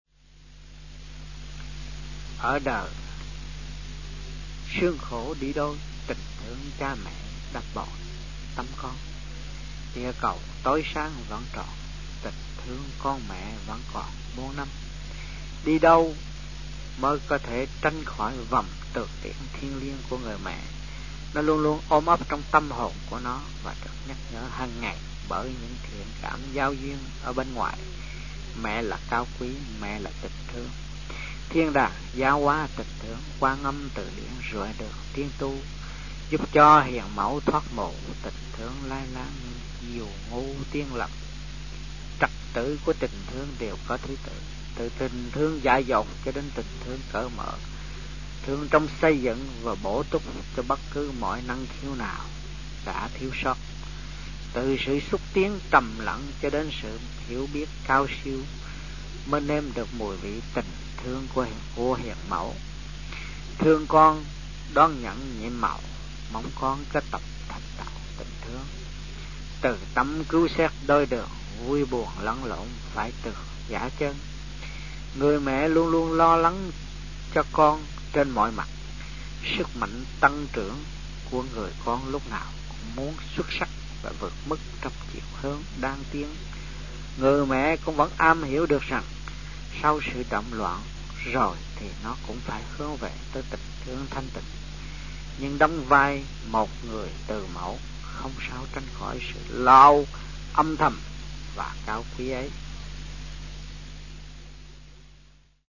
Địa danh : Sài Gòn, Việt Nam
Trong dịp : Sinh hoạt thiền đường